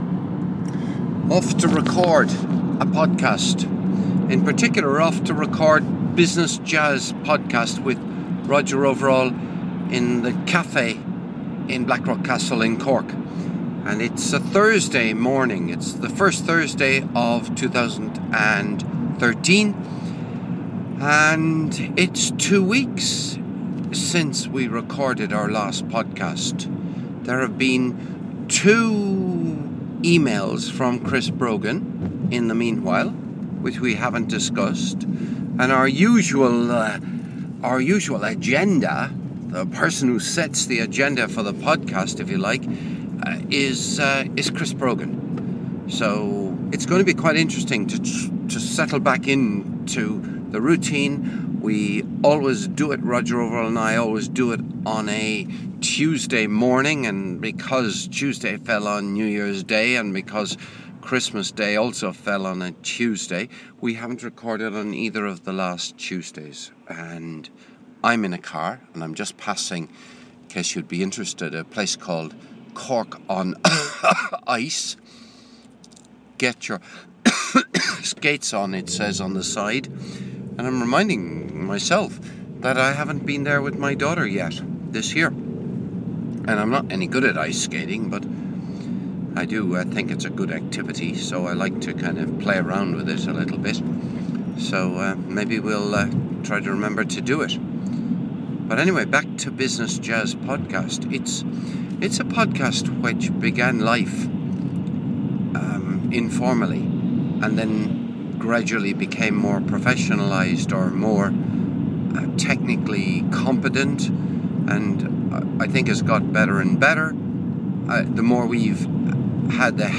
Recorded on Thursday 3 January 2013 in the car on my way to work.